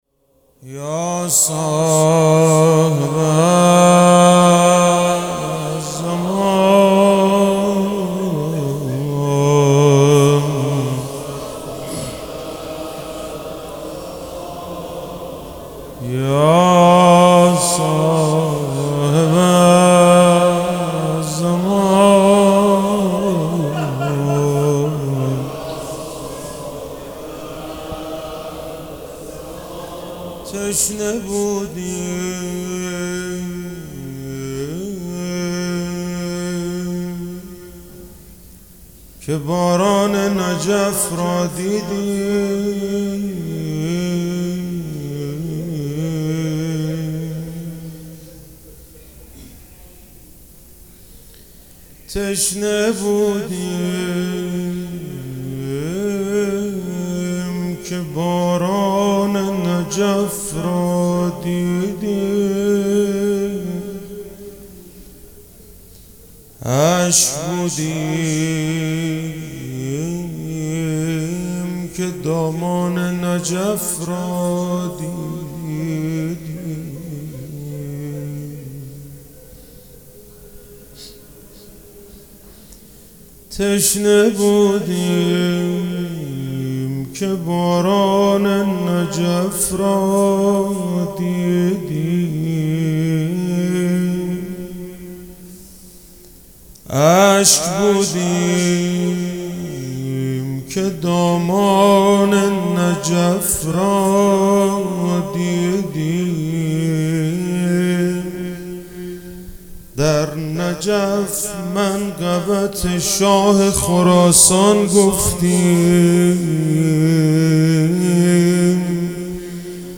شب زیارتی مخصوص امام رضا(علیه السلام)_روضه_تشنه بودیم